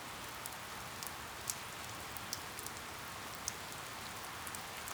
lightrain.wav